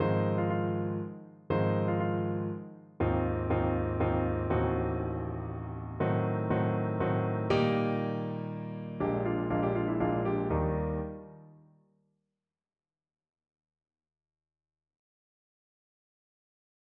베토벤 소나타 내림 마, 작품 31, 3번 오프닝